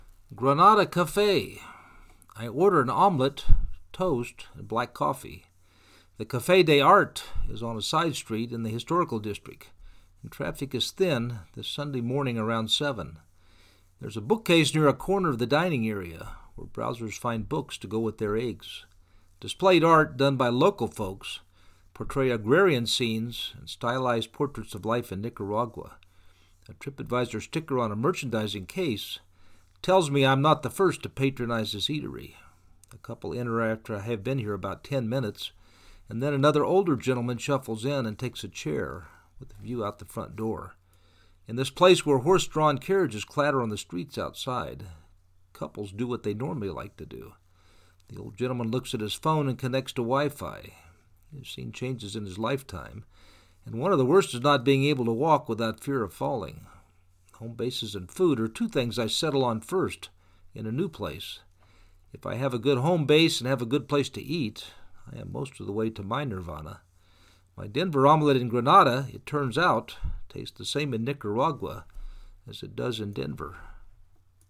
The Cafe de Arte is on a side street in the Historical District and traffic is thin this Sunday morning around seven.
In this place where horse drawn carriages clatter on the streets outside, couples do what they normally like to do.